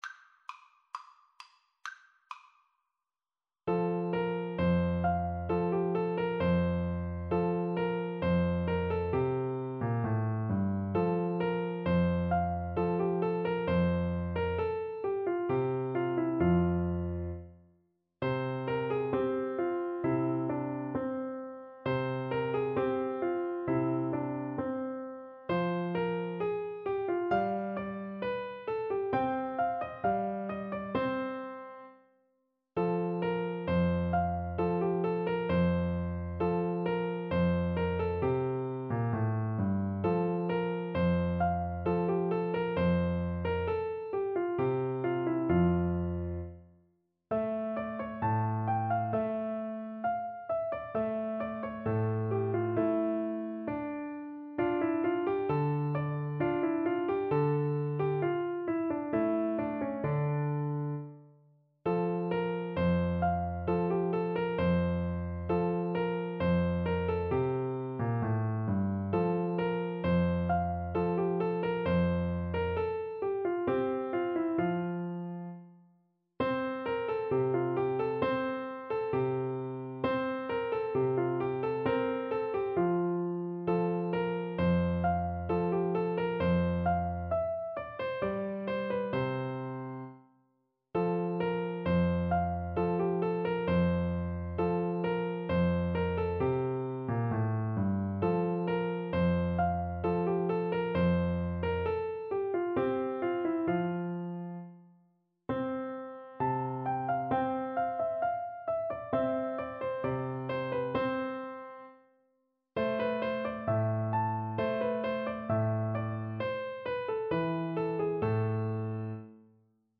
Play (or use space bar on your keyboard) Pause Music Playalong - Piano Accompaniment Playalong Band Accompaniment not yet available reset tempo print settings full screen
F major (Sounding Pitch) C major (French Horn in F) (View more F major Music for French Horn )
~ = 100 Allegretto =c.66
Classical (View more Classical French Horn Music)